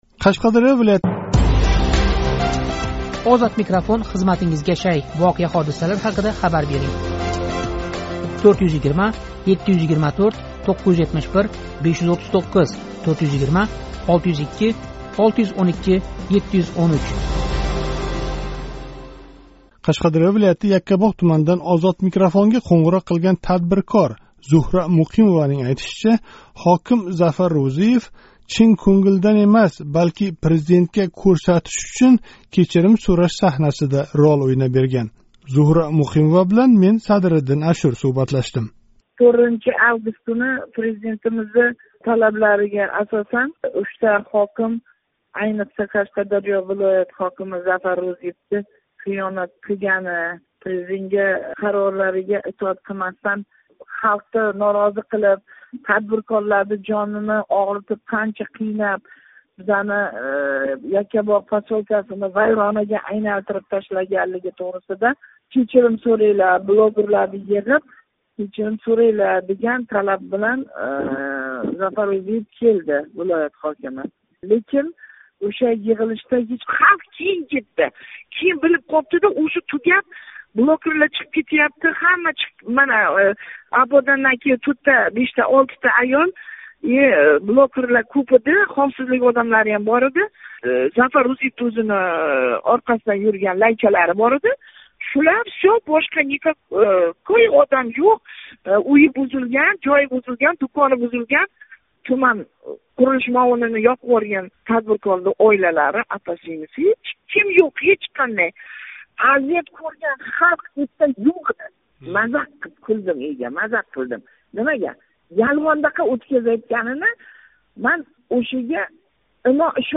қўнғироқ қилган тадбиркор